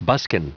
Prononciation du mot buskin en anglais (fichier audio)
Prononciation du mot : buskin